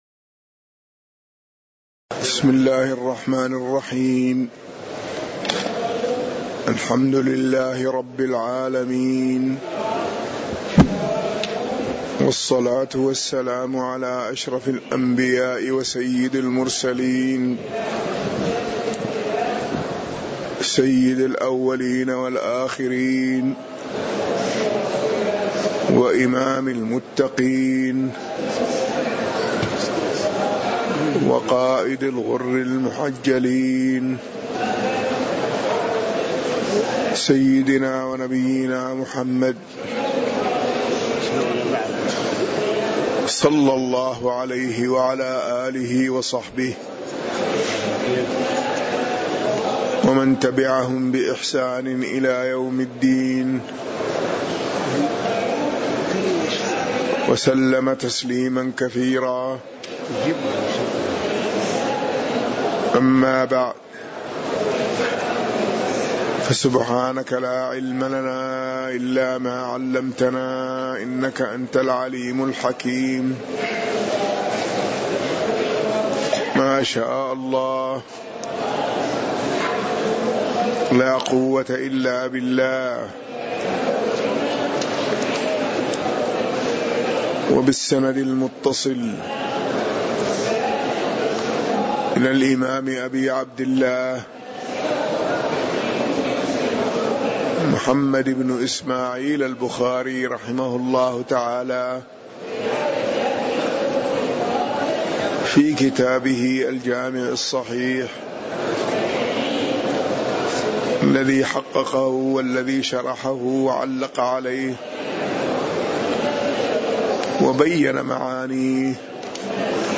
تاريخ النشر ١٣ ذو القعدة ١٤٤٠ هـ المكان: المسجد النبوي الشيخ